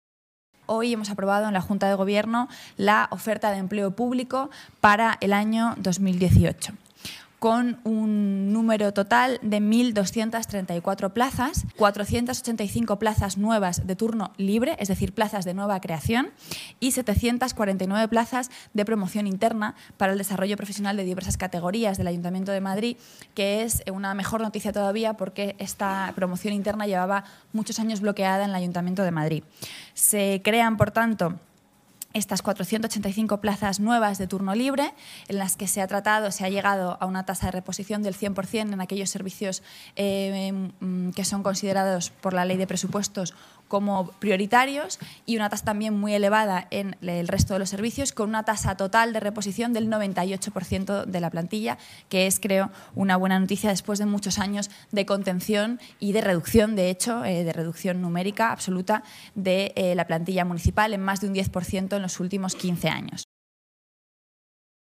Nueva ventana:La portavoz, Rita Maestre, hablando de la oferta de empleo público para 2018